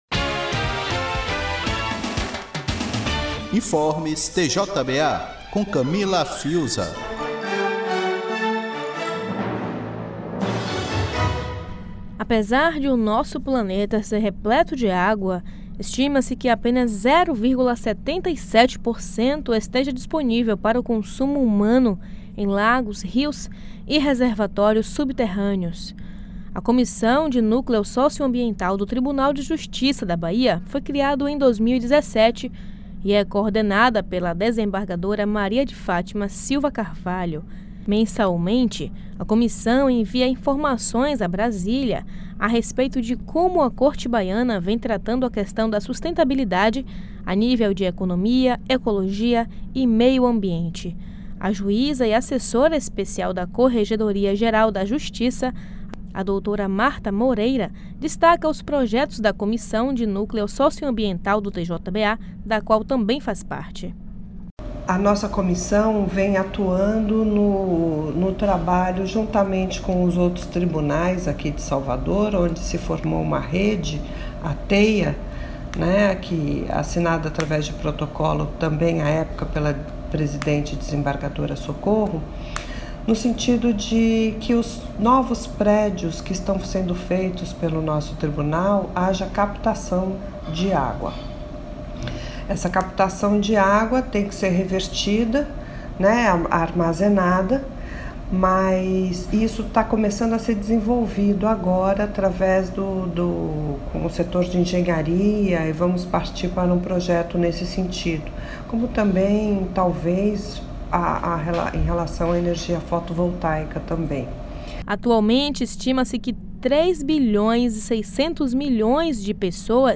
A Assembleia Geral da Organização das Nações Unidas (ONU) instituiu o dia 22 de março como o Dia Mundial da Água. Com o objetivo de conscientizar os ouvintes sobre a temática da data, a Rádio Web TJBA veicula nessa quinta-feira (22), às 15h, uma reportagem especial com a Juíza Marta Moreira, sobre as ações do Núcleo Socioambiental do Tribunal de Justiça da Bahia.